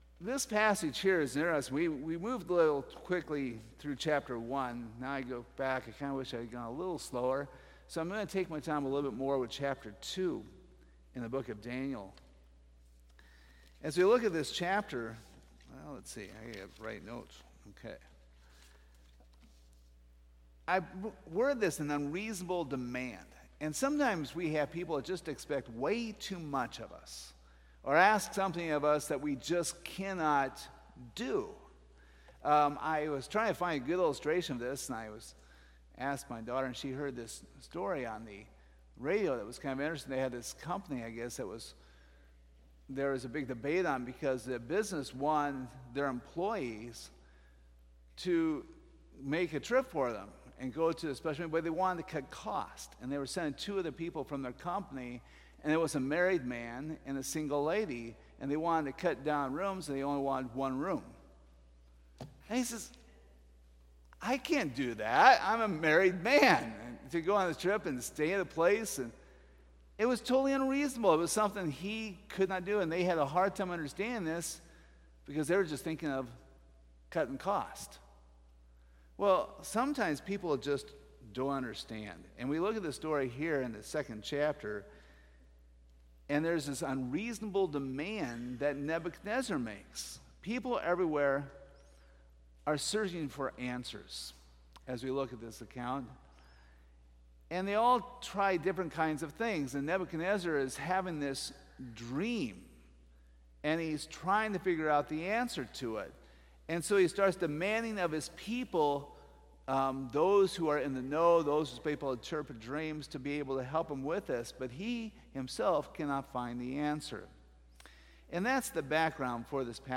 Daniel 2:1-16 Service Type: Sunday Evening Topics